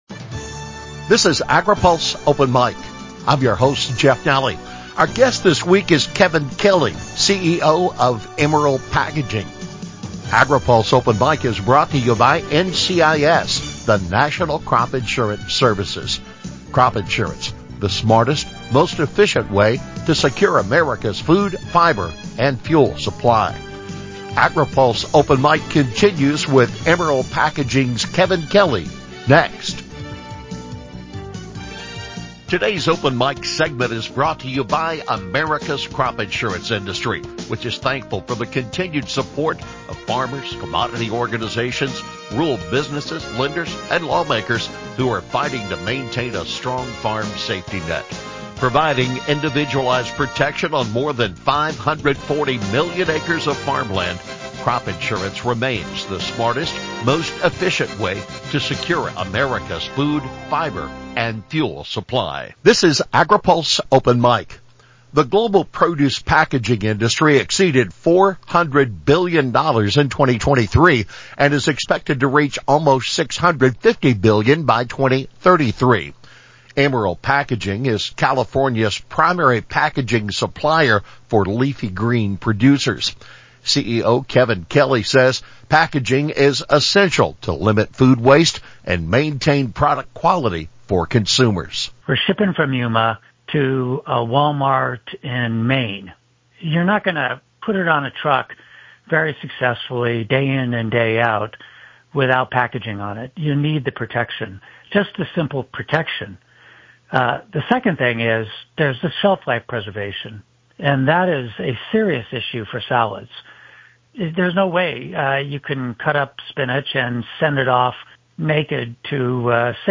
In depth interviews with leaders in ag policy